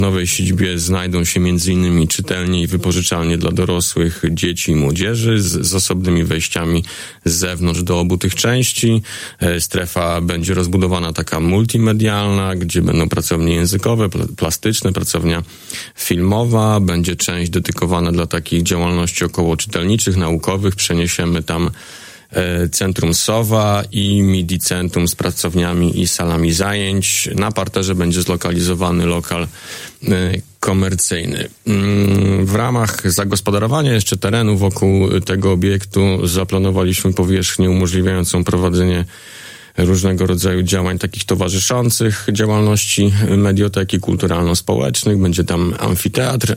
Nowa siedziba Biblioteki Publicznej imienia Marii Konopnickiej ma powstać w rozwidleniu ulic Reja i Pułaskiego. Oprócz wypożyczalni książek, pracownia kinowa i sale zajęć – wymieniał w piątek (17.01.25) w Radiu 5 Marcin Bonisławski, zastępca prezydenta Suwałk między innymi do spraw inwestycji.